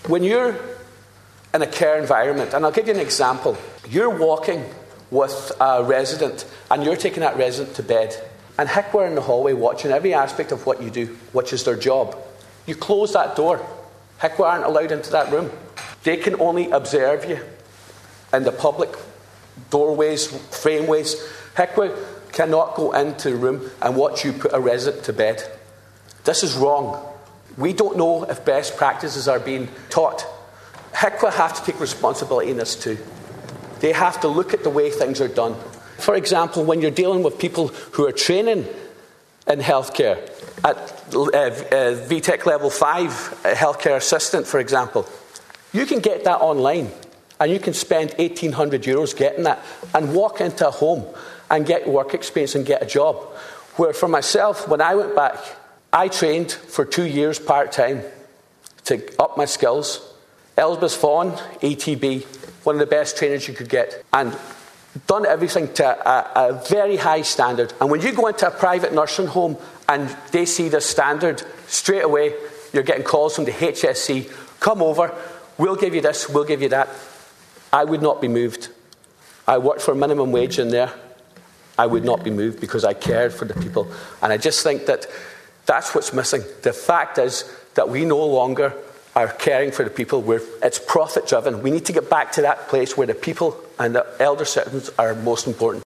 Ward outlines his own experiences working in nursing homes during Dail debate
Speaking on a Labour Party motion on the future nursing homer provision, Deputy Charles Ward outlined his experience as a healthcare worker in a dementia unit.